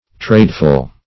Tradeful \Trade"ful\, a.